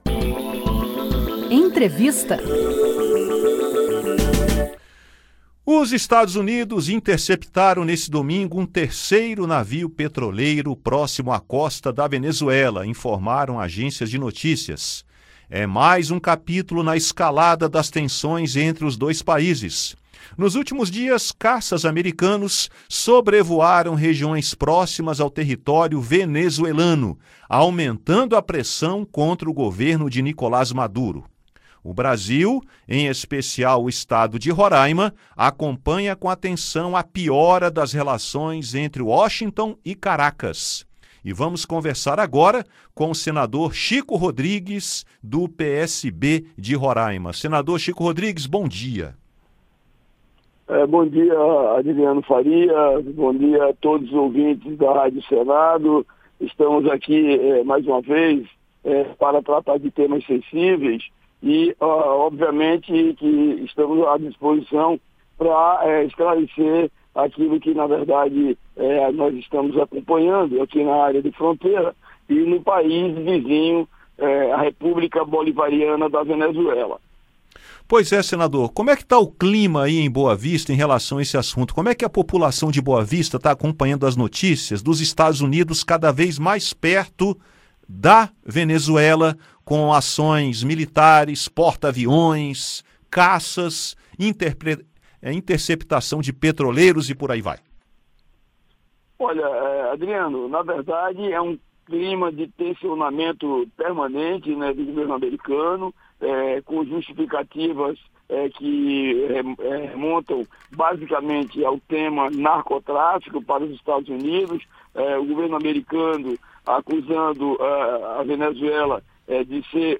No domingo (21), os EUA interceptaram um terceiro navio petroleiro próximo à costa da Venezuela, aumentando as tensões entre os dois países. O senador Chico Rodrigues (PSB-RR) fala sobre o clima no estado de Roraima e comenta o acompanhamento das autoridades brasileiras em relação à piora das relações entre Washington e Caracas. Acompanhe a entrevista.